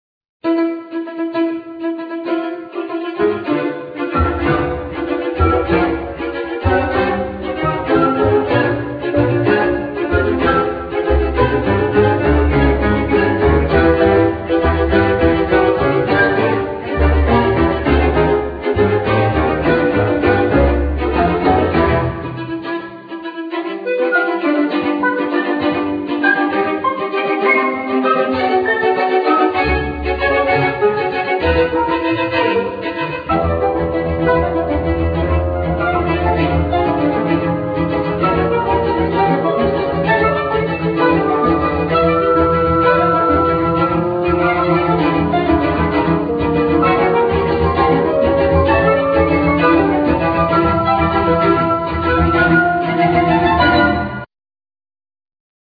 Flute
Clarinet
Basoon
Piano,Percussions
Violin,Trombone
Viola
Cello
Double bass